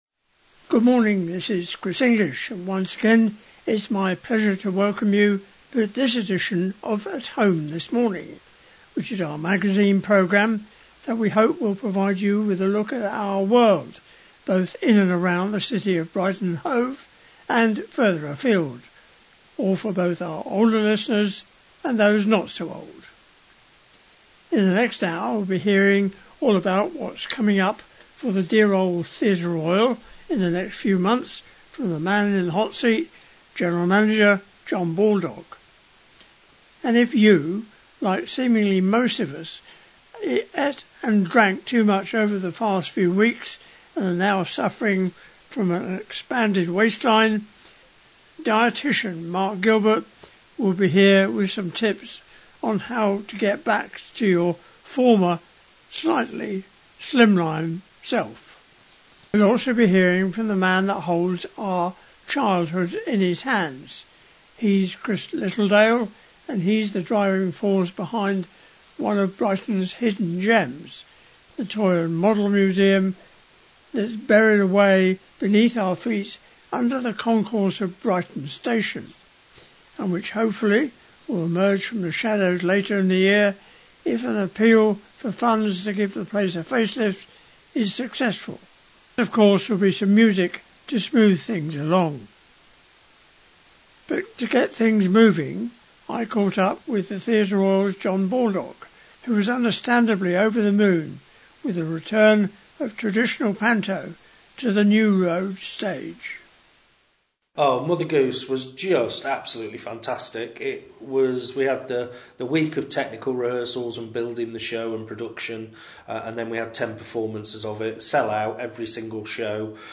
A magazine programme for older listeners